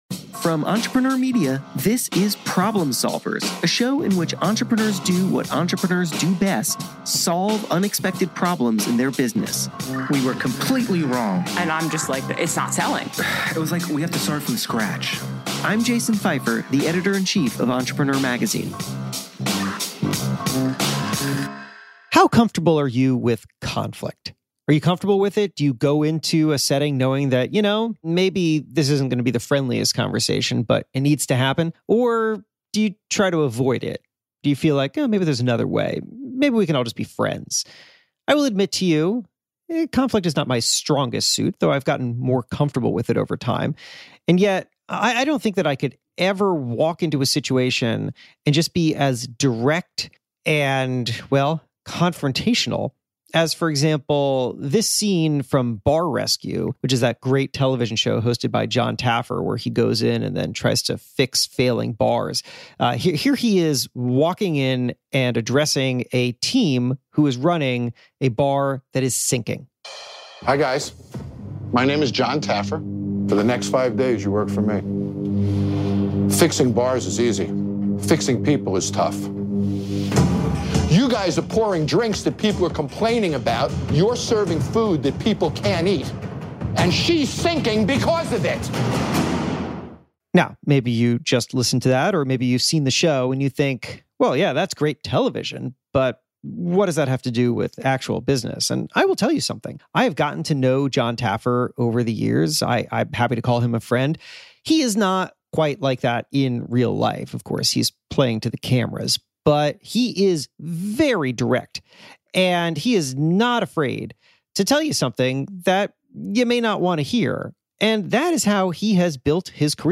In this conversation, inspired by his new book "The Power of Conflict", Taffer explains how to use conflict purposefully and constructively.